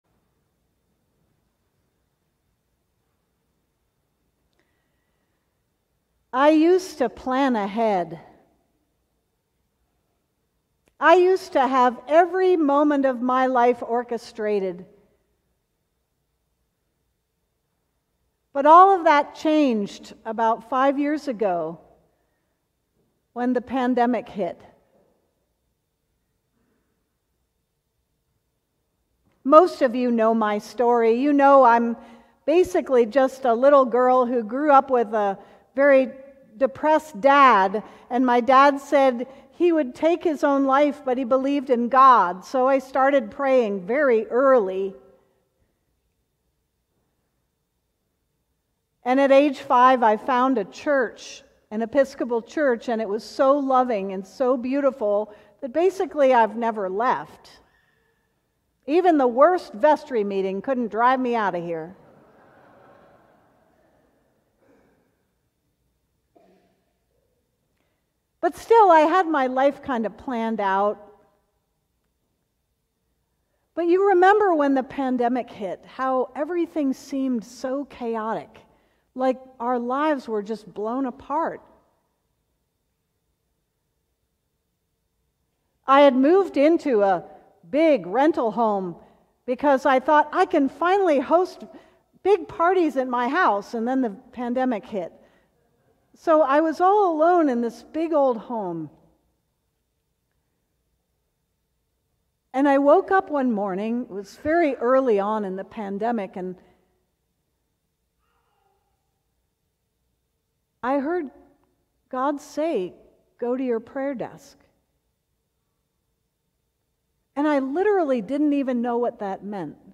Sermon: Stand with the Lamb - St. John's Cathedral